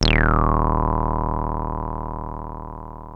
303 D#1 9.wav